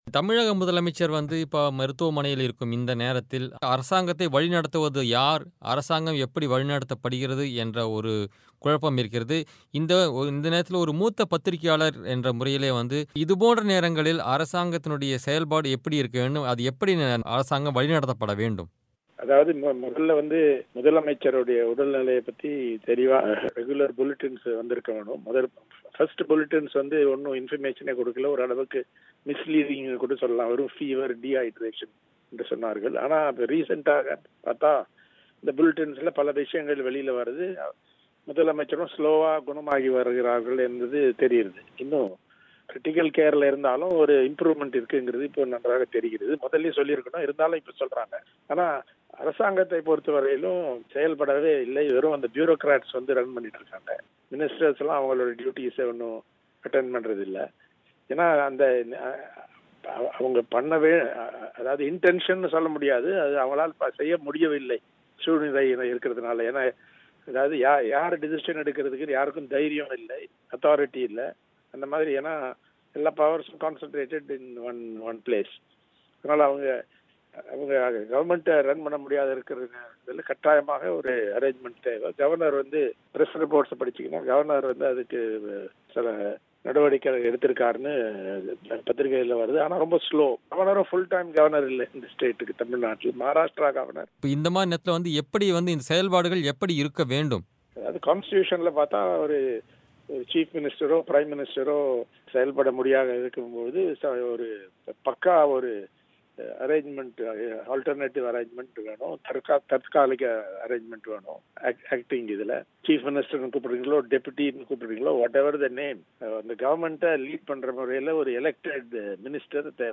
இந்த நிலையில், மாநில நிர்வாகம் முடங்கிவிடாமல் இருக்க மாற்று ஏற்பாடுகள் செய்யப்பட வேண்டியதன் அவசியம் குறித்து மூத்த பத்திரிகையாளர் `இந்து’ என். ராம் அவர்கள், பிபிசி தமிழோசைக்கு அளித்த பேட்டியை நேயர்கள் இங்கு கேட்கலாம்.